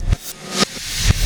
Black Hole Beat 24.wav